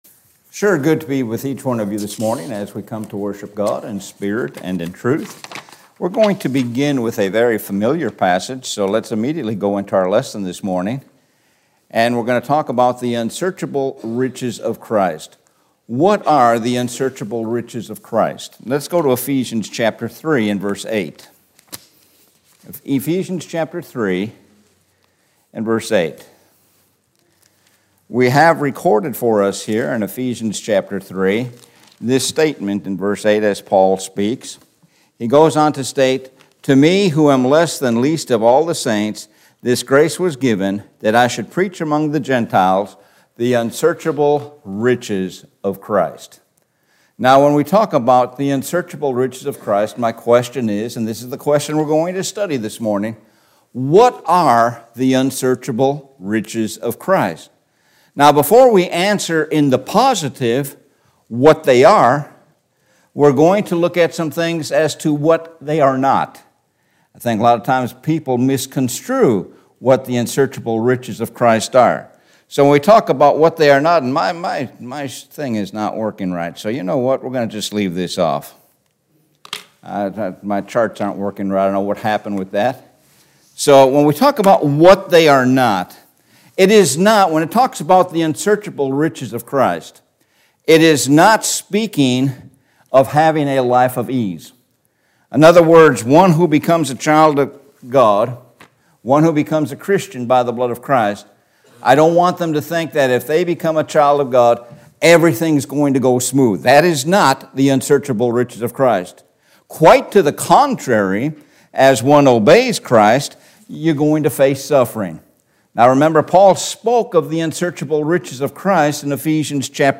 Sun AM Sermon -Unsearchable Riches of Christ